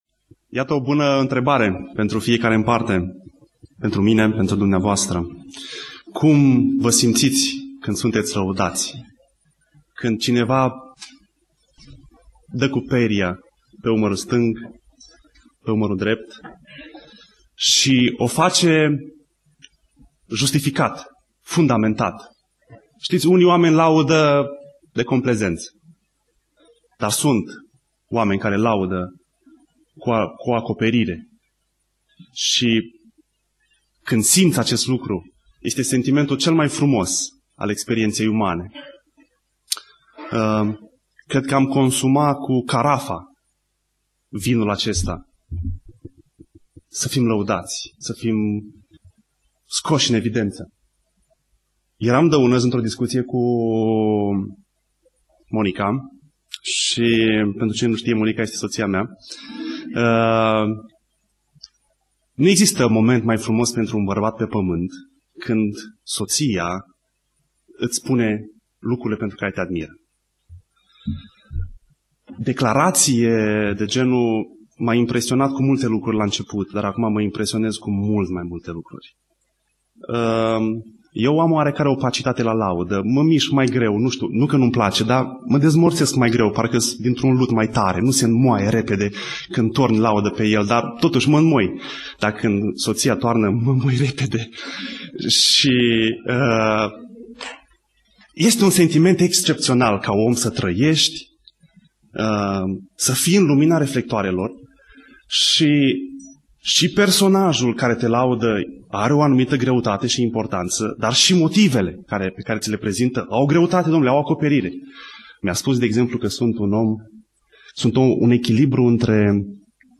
Predica Exegeza 2 Imparati cap. 8b-10